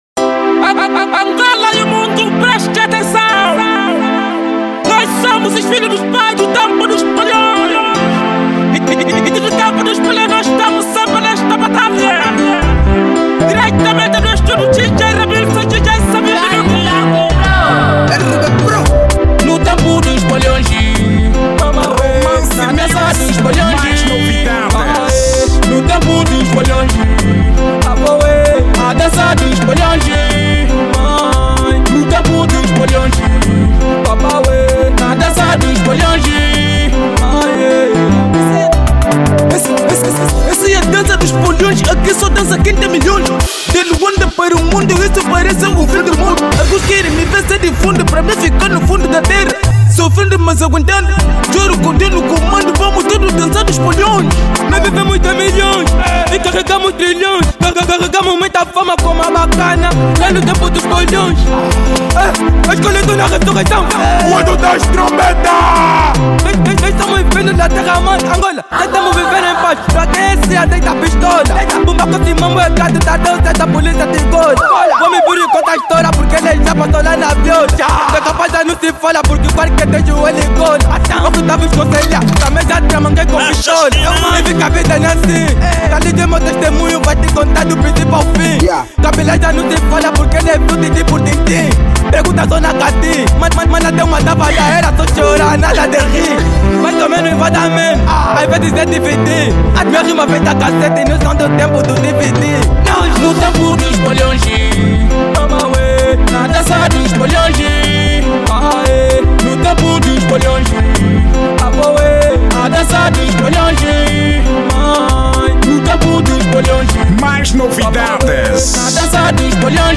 Estilo: Kuduro